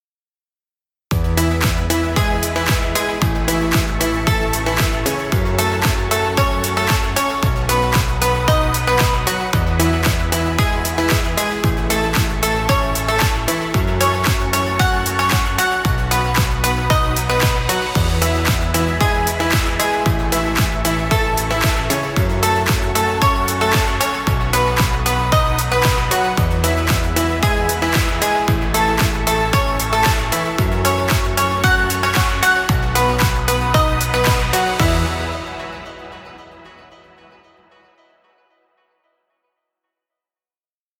Happy motivational music. Background music Royalty Free.